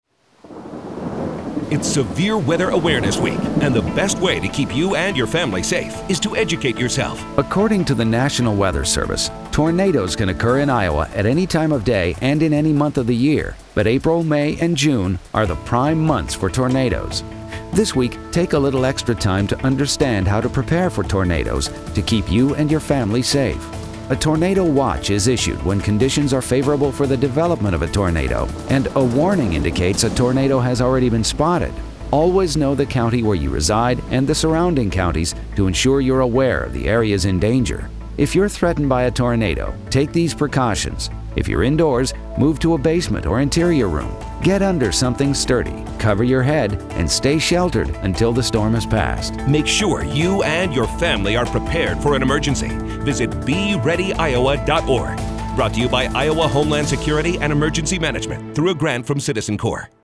PSA_SWAW_Tornadoes.mp3